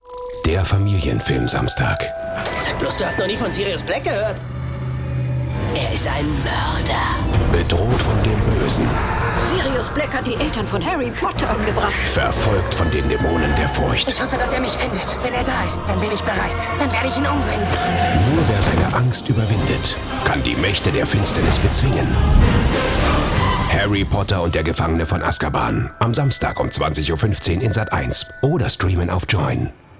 dunkel, sonor, souverän, markant
Station Voice